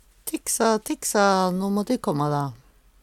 DIALEKTORD PÅ NORMERT NORSK tiksa, tiksa lokking på sauer Eksempel på bruk Tiksa, tiksa, tiksa, - no må de kåmma`ra!